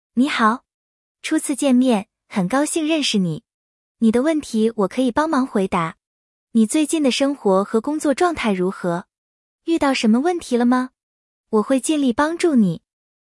• 语音合成：TTS 结果保存到本地；
• 音频播放：把保存在本地的 TTS 结果，通过蓝牙音箱播放。
最后播报的音频结果：